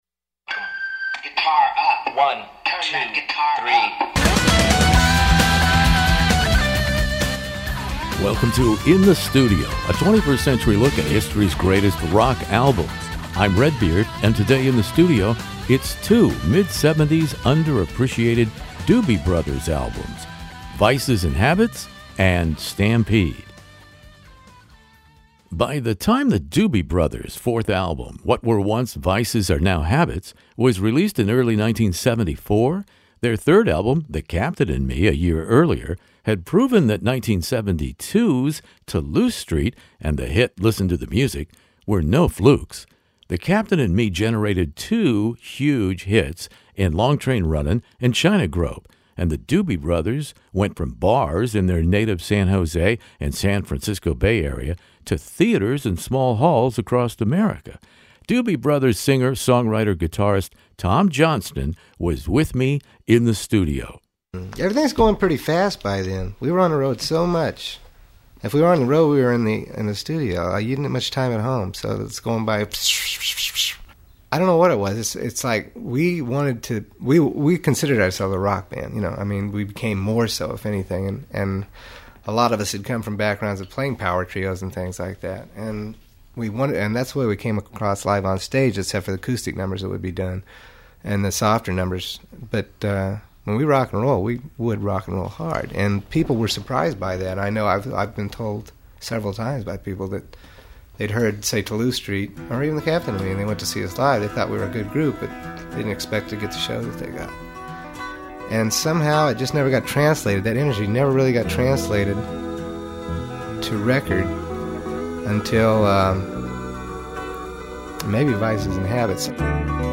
Simmons and Doobie Brothers prodigal son Tom Johnston join me In the Studio in this classic rock interview.